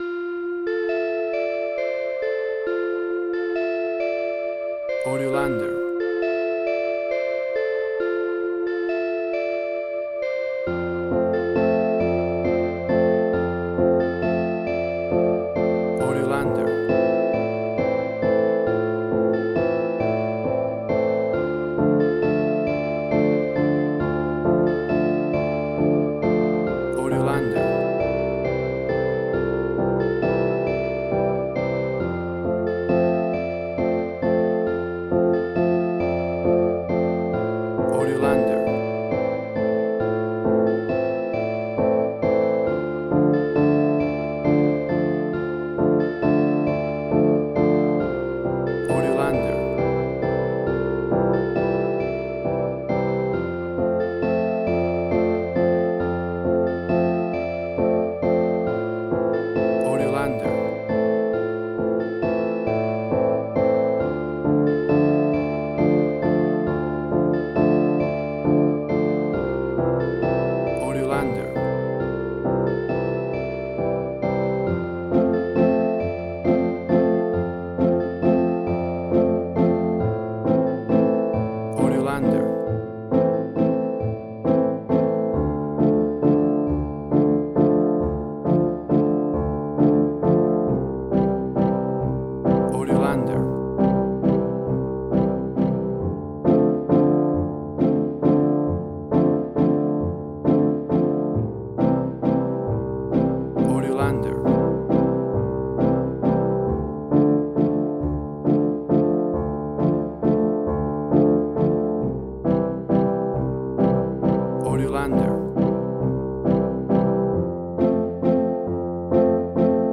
Suspense, Drama, Quirky, Emotional.
Tempo (BPM): 135